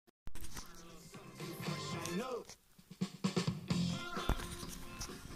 Piosenka z radia
tylko koncowke zlapalem